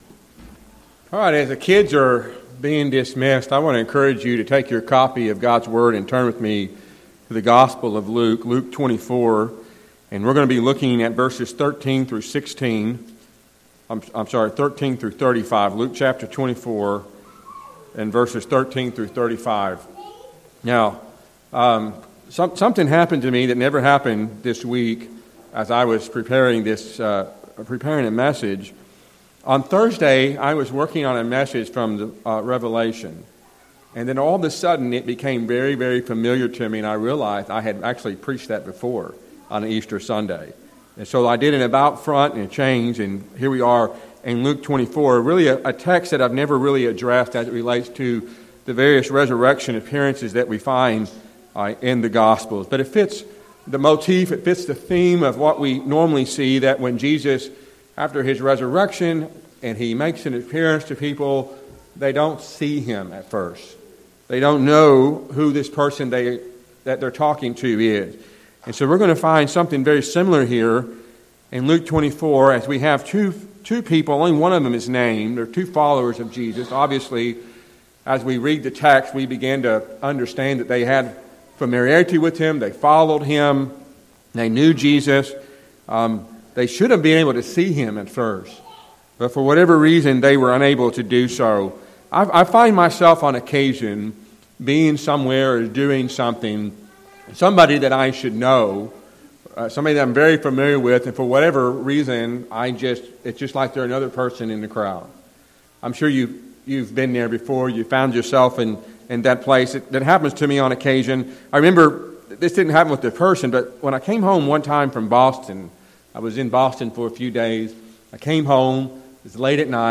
A sermon from Luke 24:13-35